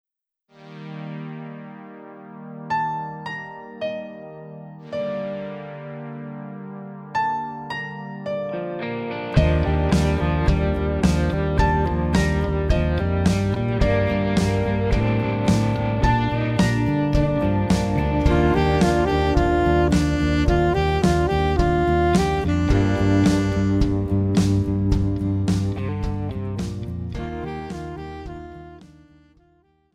Voicing: Piano Accompaniment